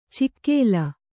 ♪ cikkēla